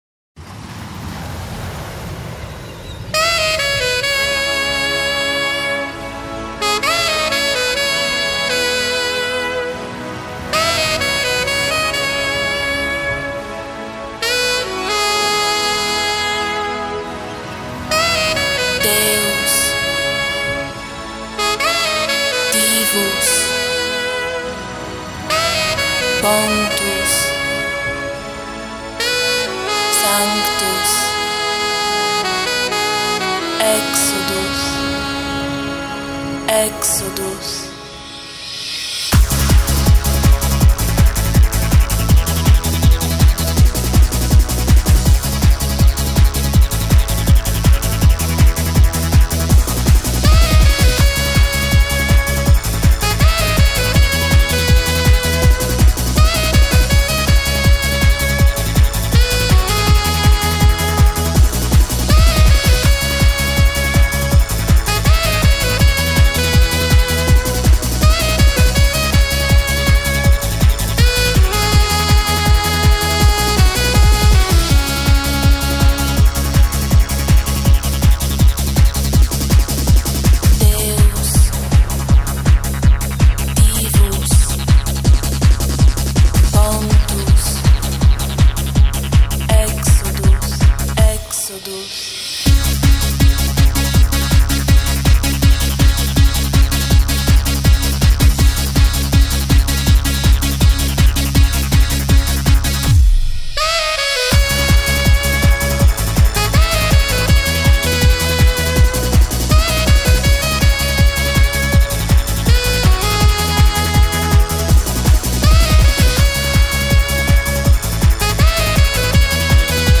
Жанр: Eurodance